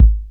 • 2000s Sharp Rap Kick Drum Single Hit G# Key 308.wav
Royality free kick drum sample tuned to the G# note. Loudest frequency: 69Hz